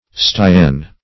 styan - definition of styan - synonyms, pronunciation, spelling from Free Dictionary Search Result for " styan" : The Collaborative International Dictionary of English v.0.48: Styan \Sty"an\, n. See Sty , a boil.